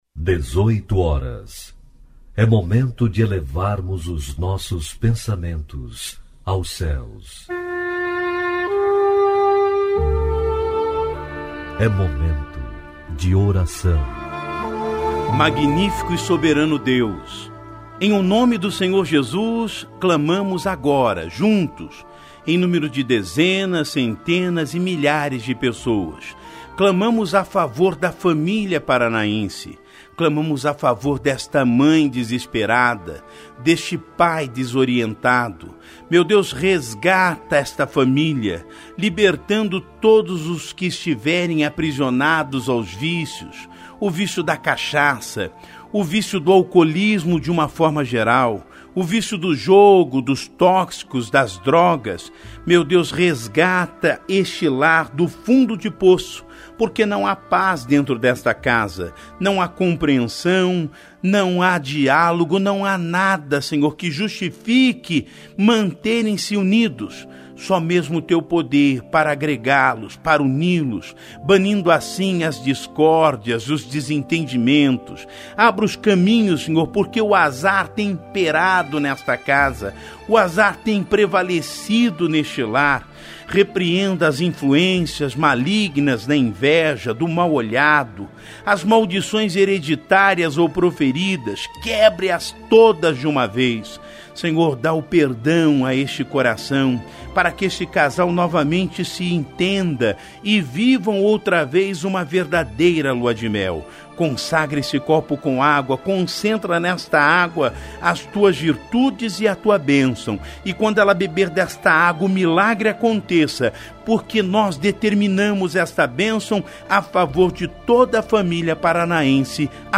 Oração pelos deprimidos